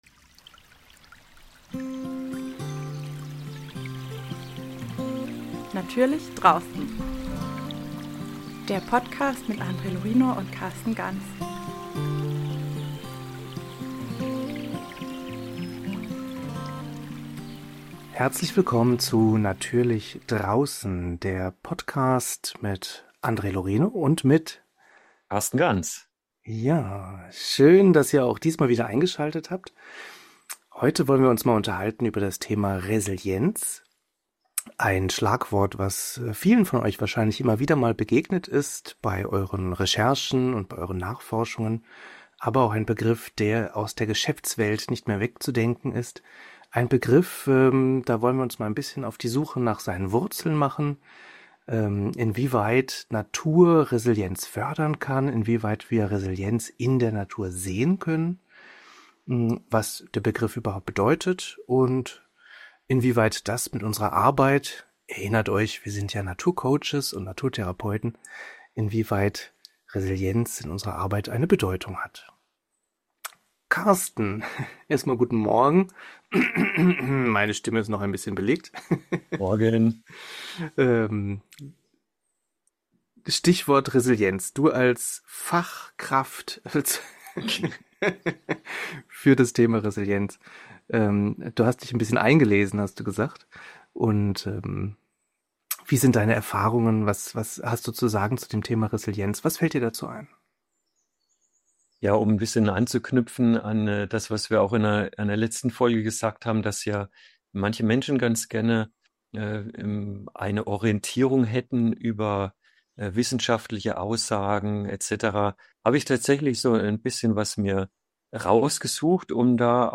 Resilienz meint, mit den Widrigkeiten des Lebens gut umzugehen. Was das genau im Kontext von gesellschaft und Natur heißt, wo eigentlich die Fallstricke dabei liegen, und welche Aspekte dabei oft unterschätzt und doch unerlässlich sind, darüber handelt unser heutiges Gespräch.